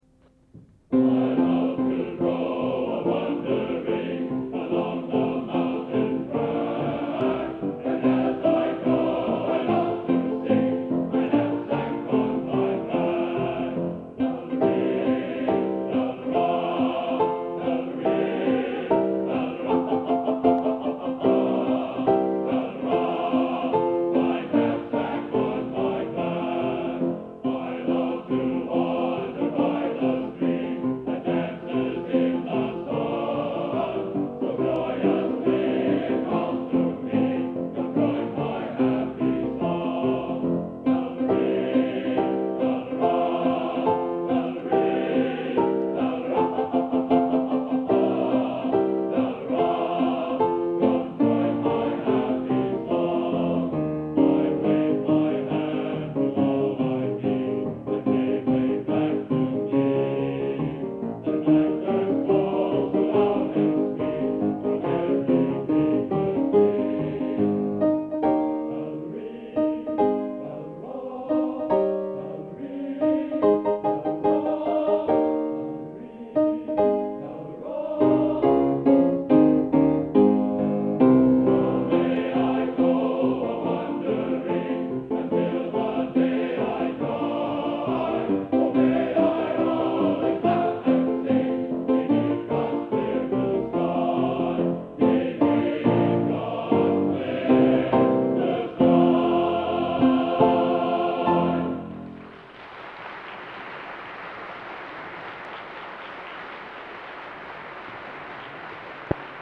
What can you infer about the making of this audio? Location: Plymouth, England